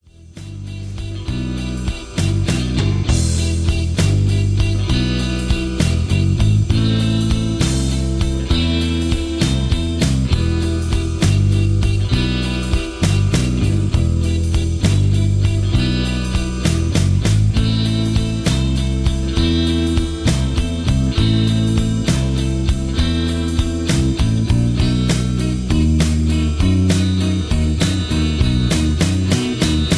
backing tracks
rock and roll, r and b